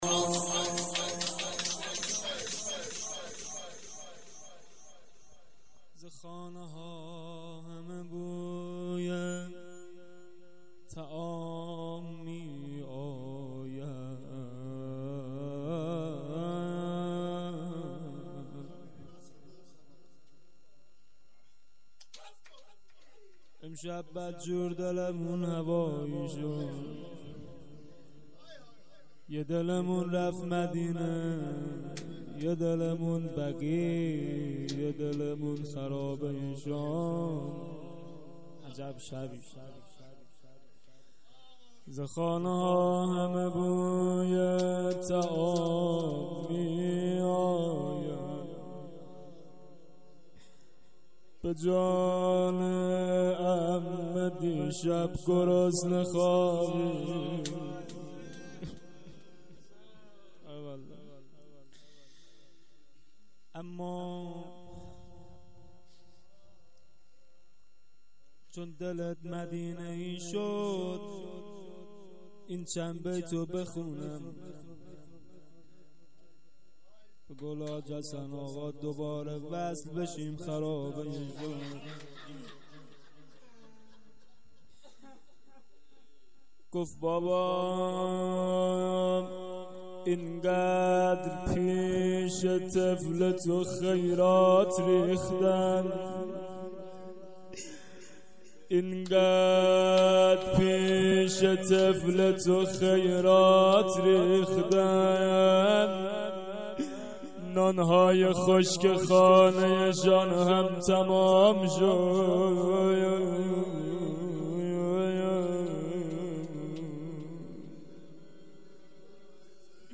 روضه حضرت رقیه(س)